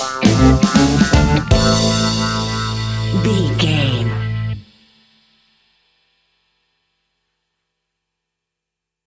A hot sunshing BBQ with the backdrop of island reggae Music!
Aeolian/Minor
Slow
instrumentals
laid back
chilled
off beat
drums
skank guitar
hammond organ
percussion
horns